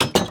Minecraft Version Minecraft Version latest Latest Release | Latest Snapshot latest / assets / minecraft / sounds / block / iron_door / close3.ogg Compare With Compare With Latest Release | Latest Snapshot